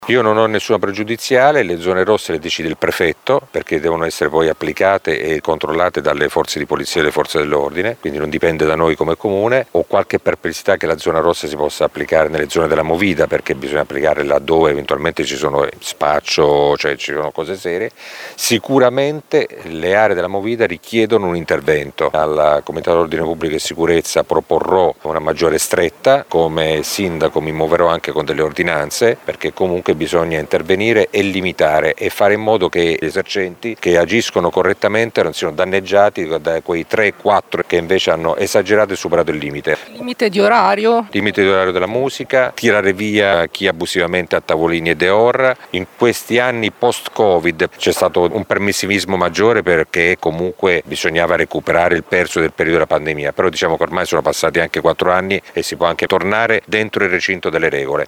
Sul tema della sicurezza è intervenuto il sindaco di Modena Massimo Mezzetti, che prevede nuove ordinanze e controlli più serrati anche su musica e tavolini abusivi: